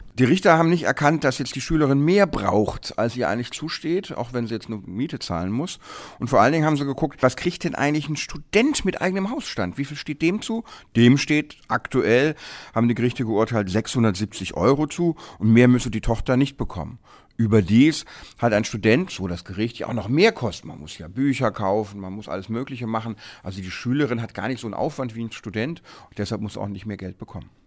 O-Ton: Unterhalt für allein lebende Minderjährige